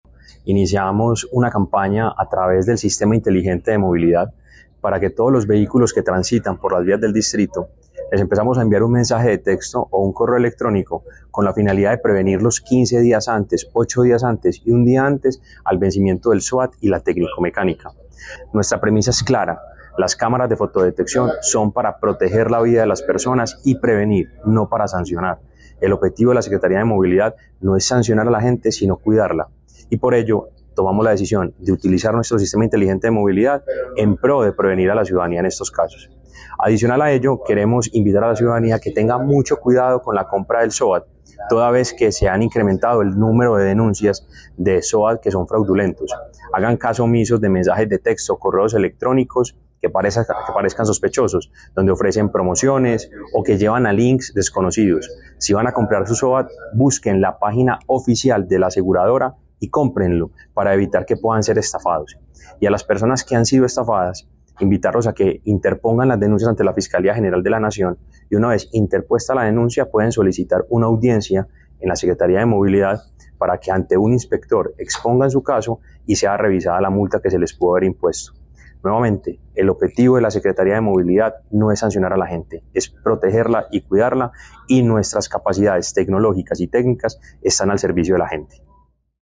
Palabras de Mateo González Benítez, secretario de Movilidad